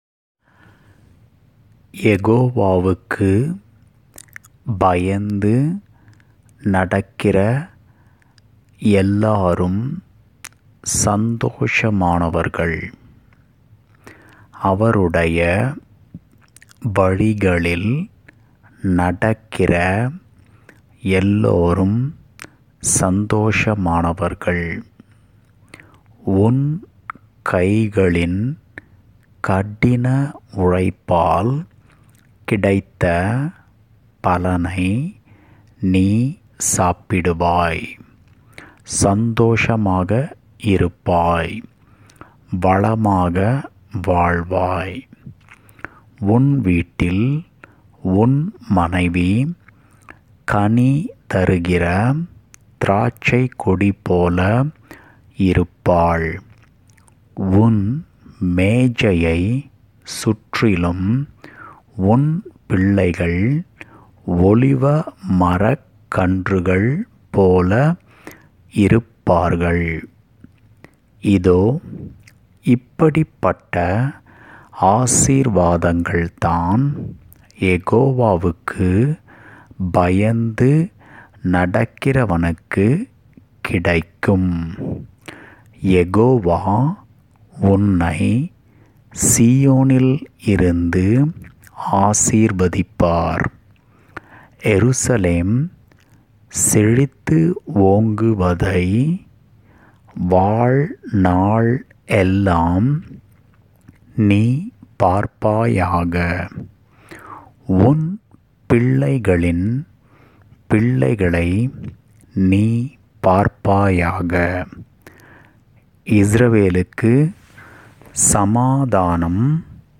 psalms128_tamilreading.mp3